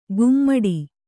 ♪ gummaḍi